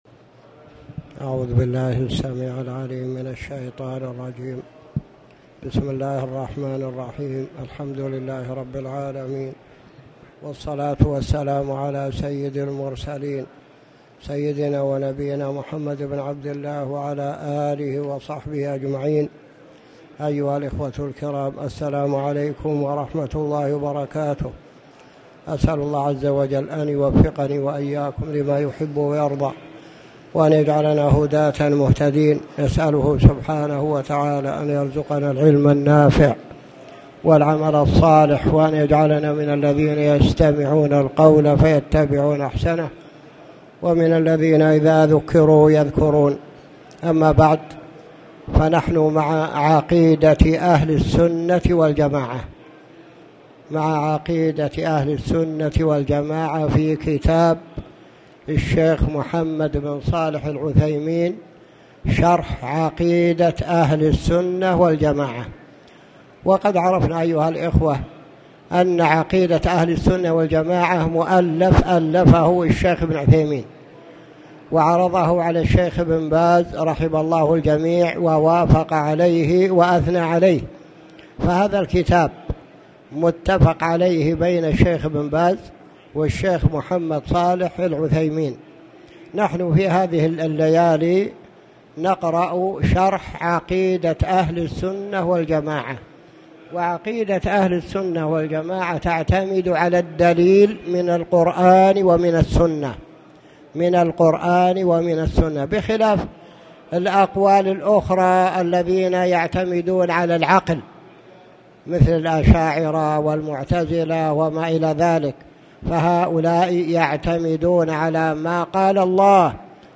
تاريخ النشر ٢٥ شعبان ١٤٣٨ هـ المكان: المسجد الحرام الشيخ